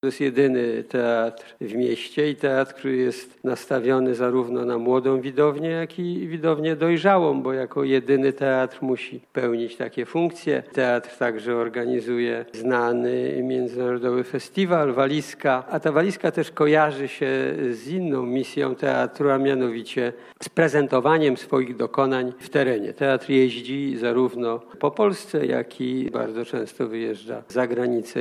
Minister kultury i dziedzictwa narodowego Piotr Gliński podkreślił, że TLiA jest to szczególnie ważna instytucja kultury w mieście.